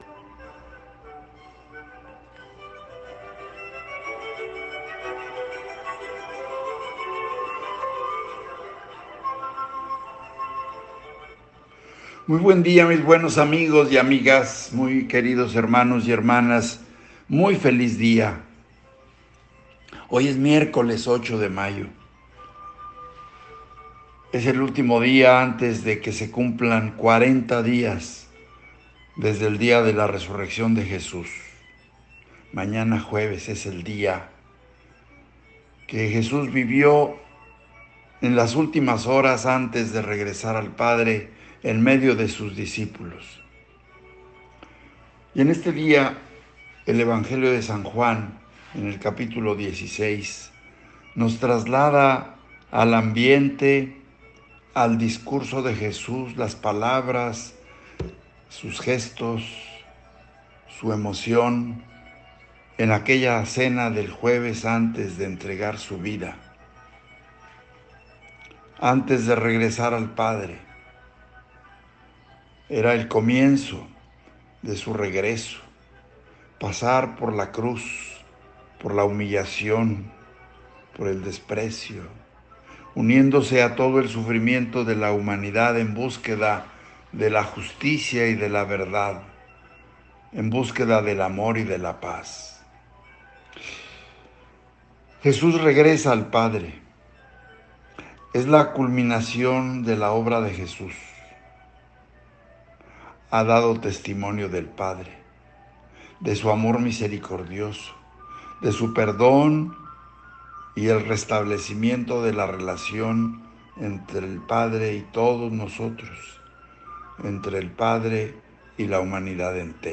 Reflexión del Evangelio